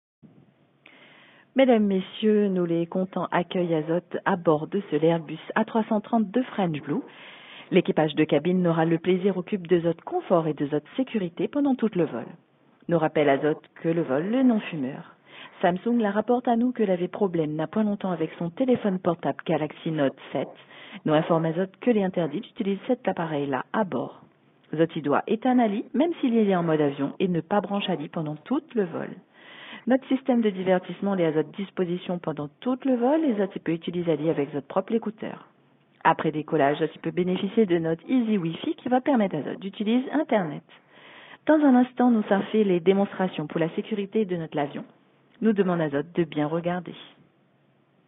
Et, en clin d’œil à la destination et en plus du français et de l’anglais,
des annonces en créole tout à fait attendrissantes.